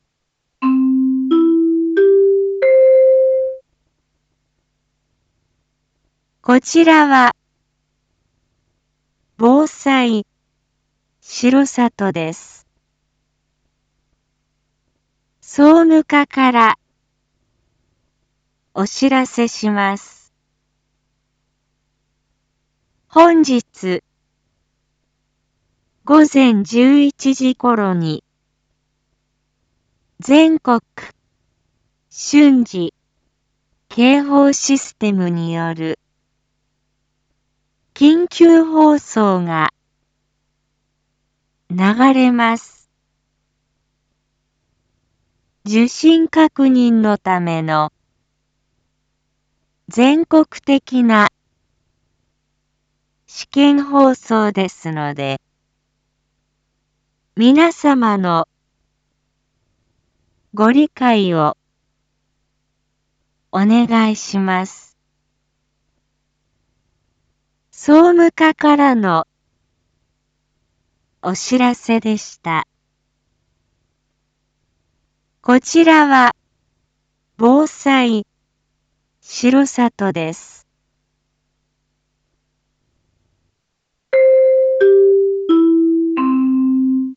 Back Home 一般放送情報 音声放送 再生 一般放送情報 登録日時：2021-10-06 07:01:28 タイトル：R3.10.6 放送分 インフォメーション：こちらは防災しろさとです。